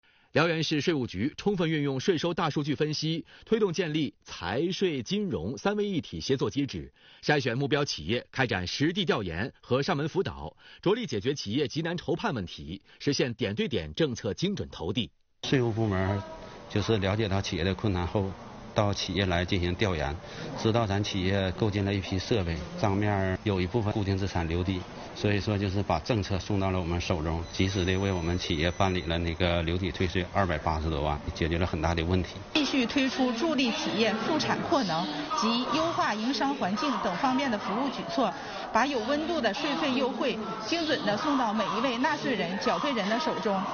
6月5日吉林新闻联播播报：辽源市税务局聚焦市场主体，贯彻落实新的组合式税费支持政策，不断优化税收营商环境，积极解决企业“愁、难、急、盼”问题，把“有温度”的税费优惠政策送到每一位纳税人缴费人手中。